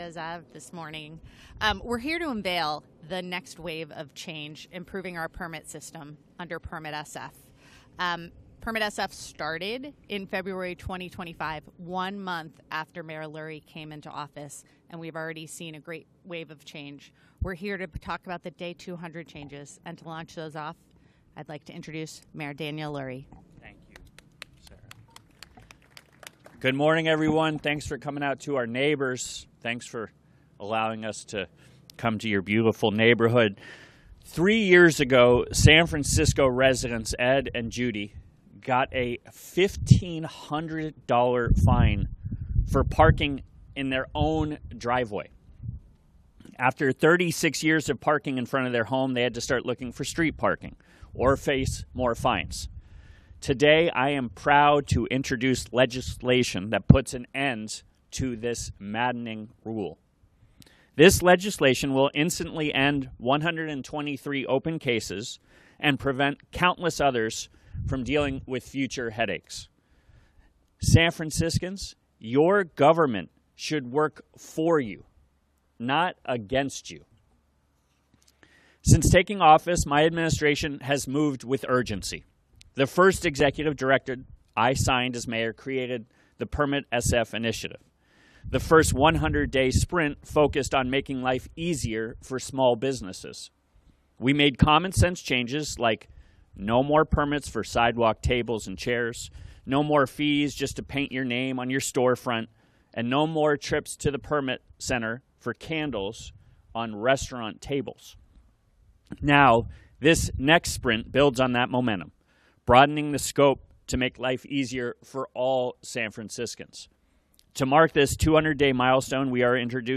Mayor's Press Conference Audio Podcast